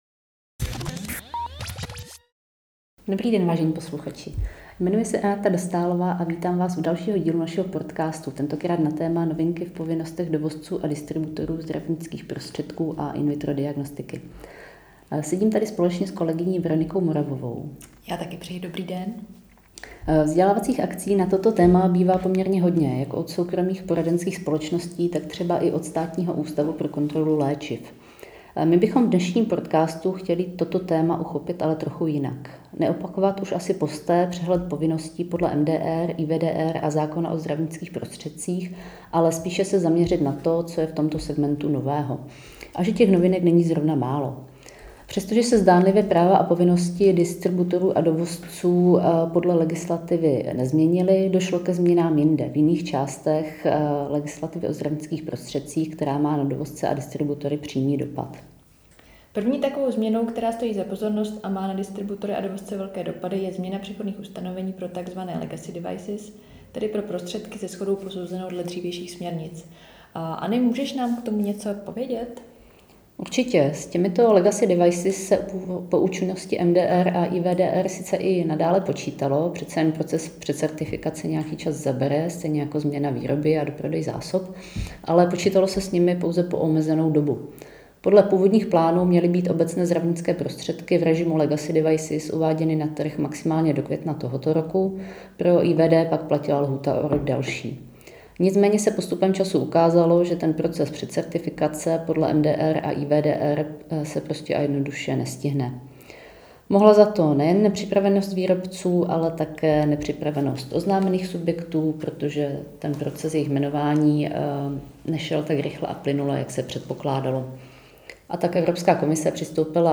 Cílem cca desetiminutového rozhovoru je posluchače stručně seznámit s praktickými zkušenostmi při plnění legislativních povinností.